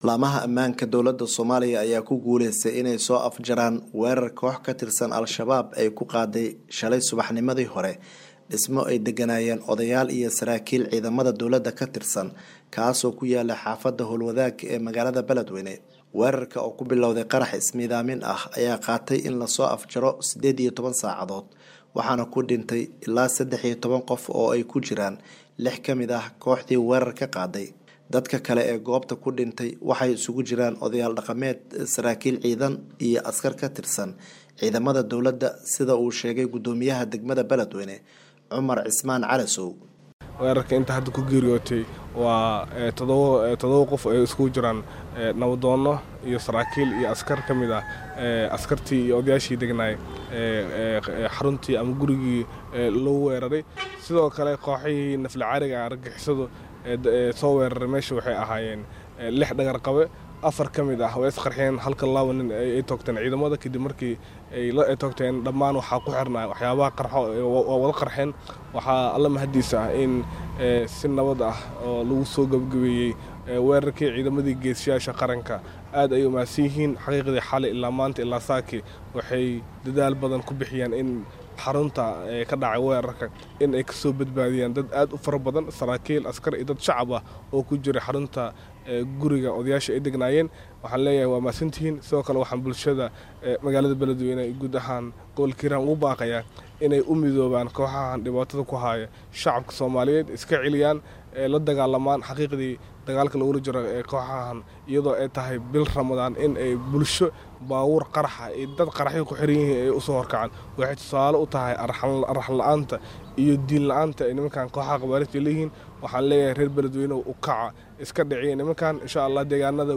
Warbixin ku saabsan weerarkaas waxaa Beledweyne ka soo diray wariyaha VOA